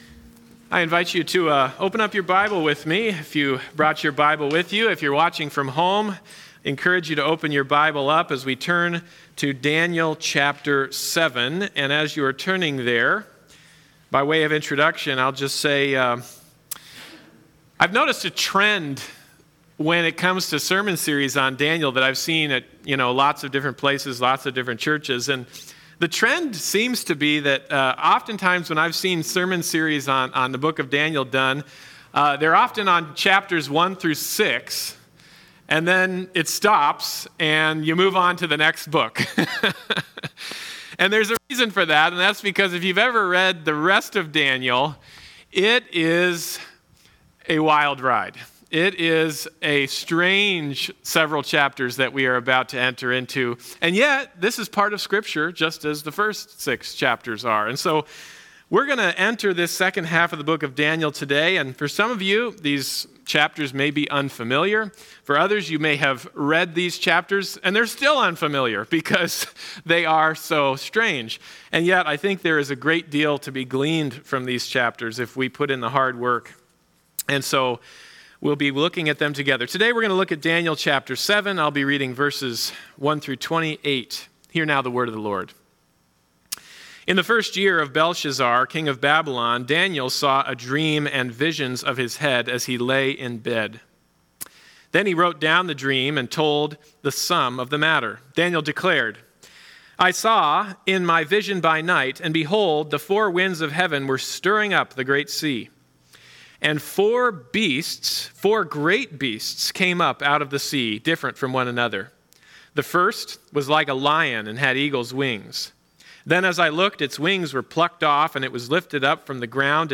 Bible Text: Daniel 7:1-28 | Preacher